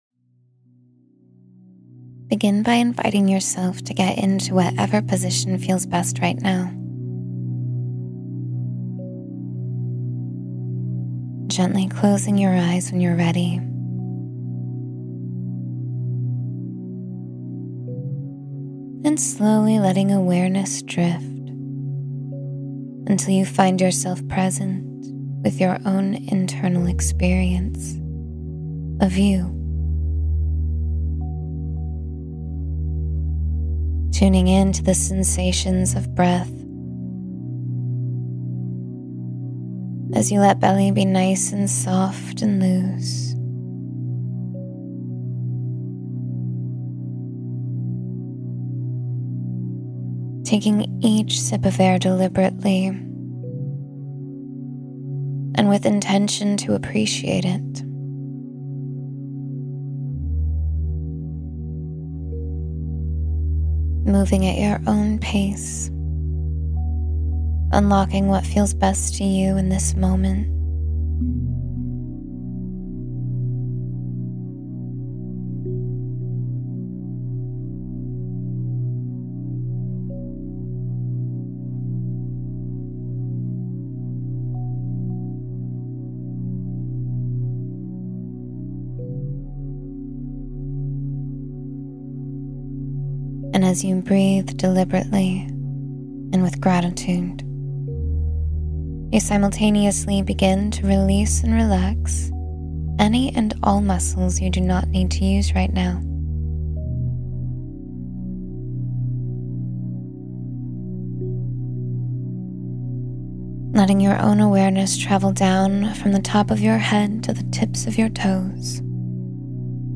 Drift Into Deep, Peaceful Sleep — Guided Meditation for Rest and Relaxation